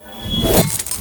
bsword2.ogg